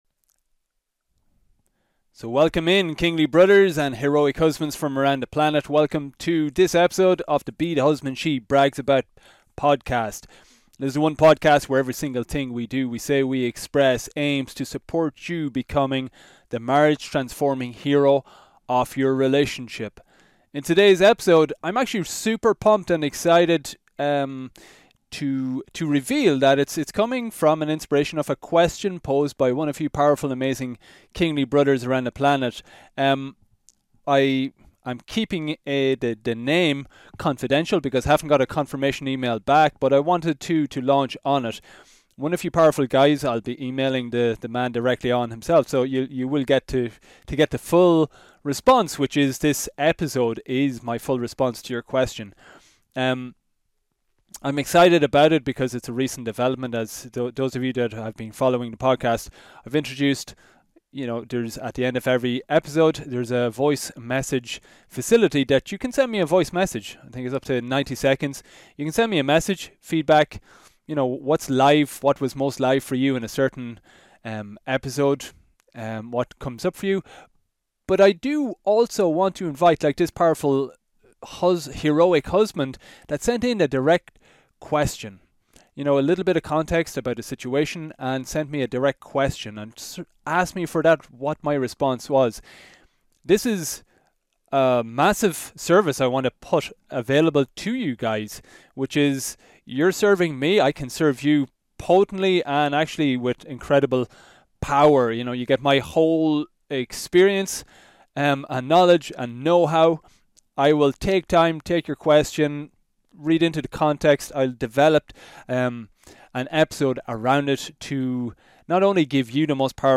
This Q&A special episode is a direct response to an amazing question posed by one courageous Heroic Husband listening in each week.